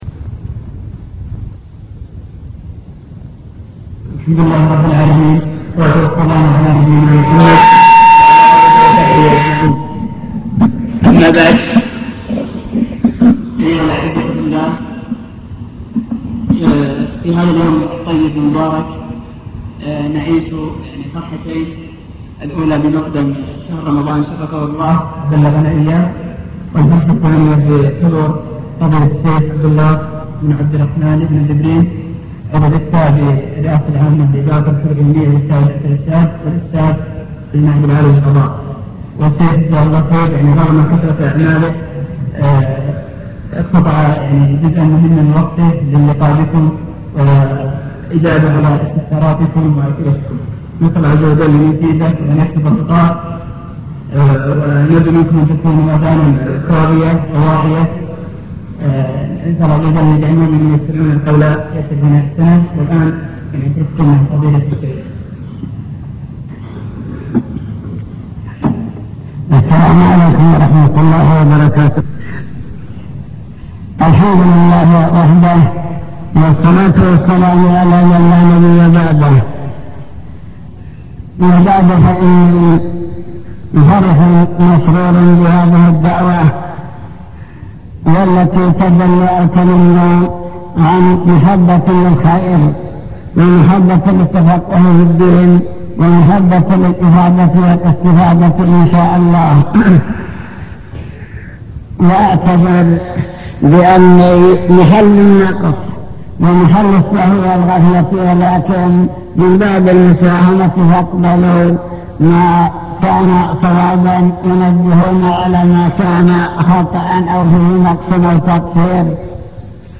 المكتبة الصوتية  تسجيلات - محاضرات ودروس  مجموعة محاضرات ودروس عن رمضان